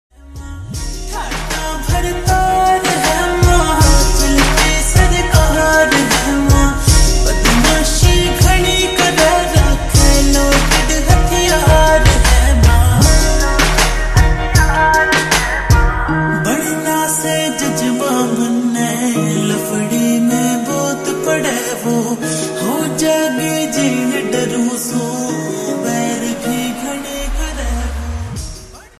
Haryanvi Song
(Slowed + Reverb)